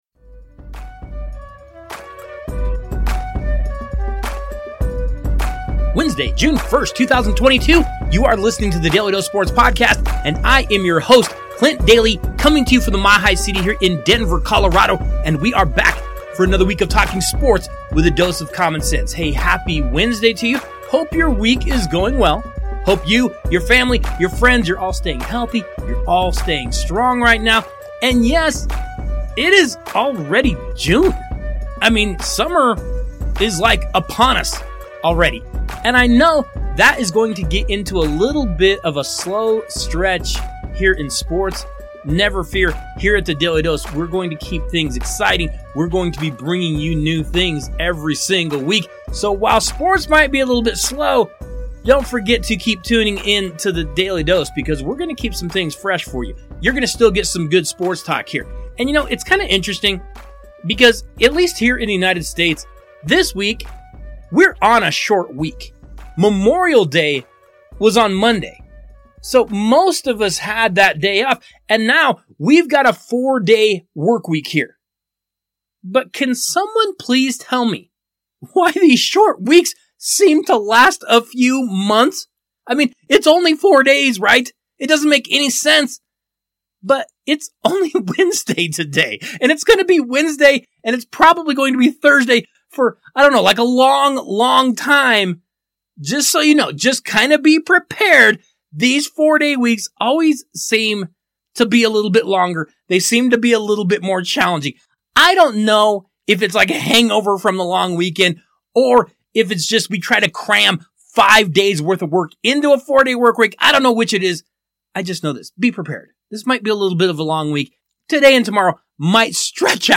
Our interview